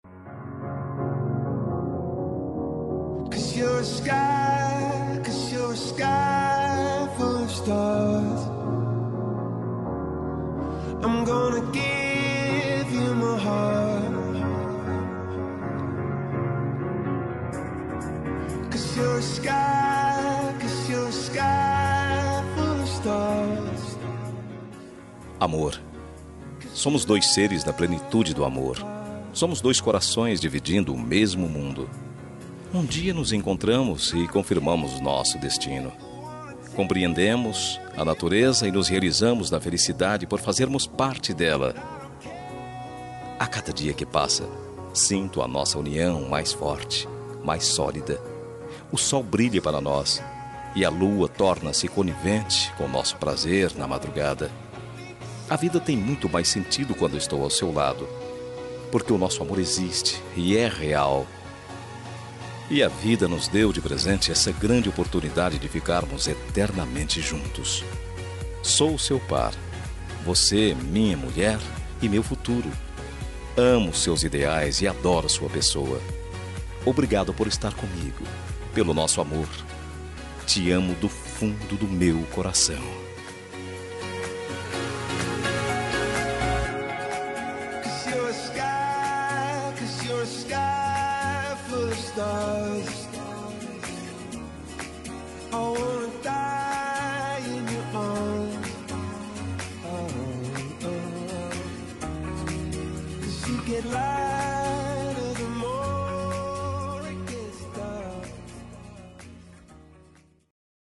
Telemensagem Romântica – Esposa – Voz Masculina – Cód: 7875